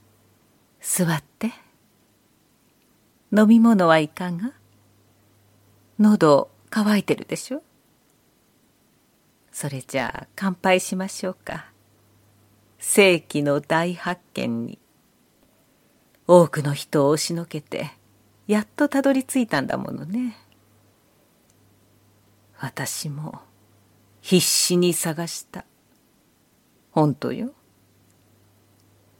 ボイスサンプル
女性(静かめ)